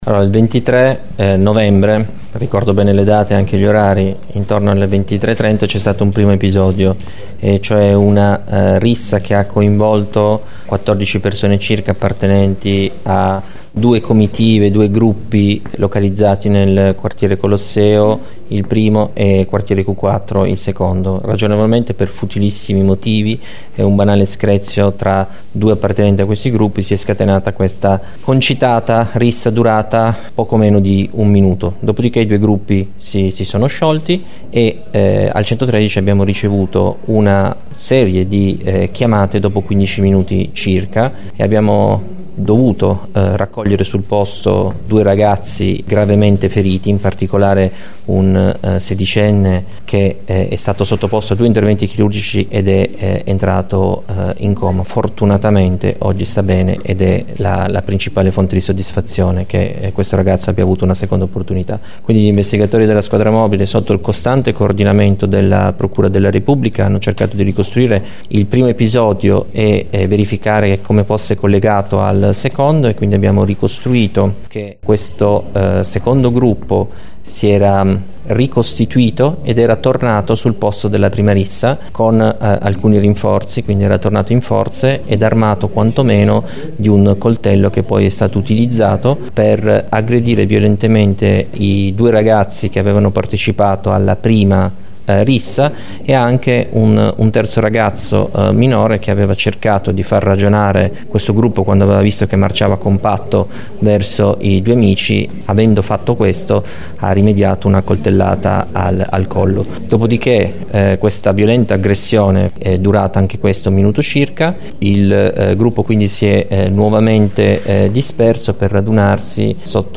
durante la conferenza stampa di questa mattina